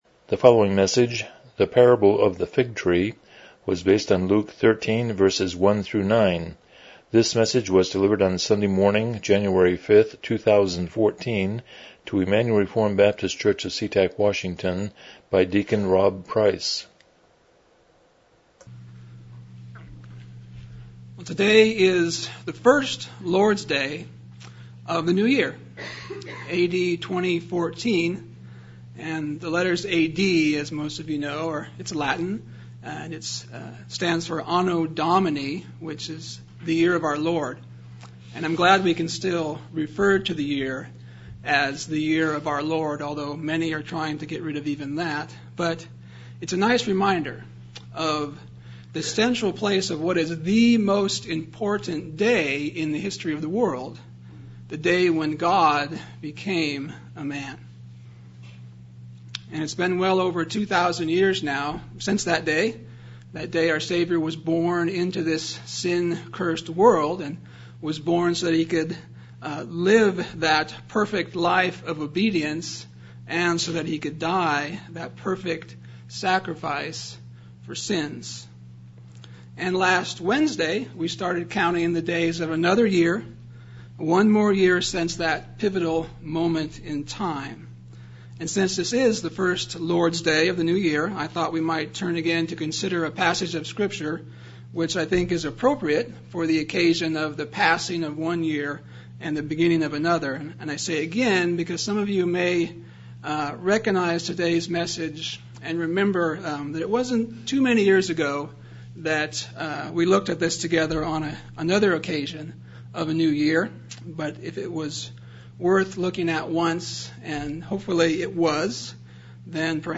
Luke 13:1-9 Service Type: Morning Worship « 25 The Sovereignty of God